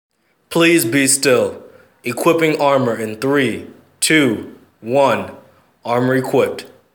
equipSound.wav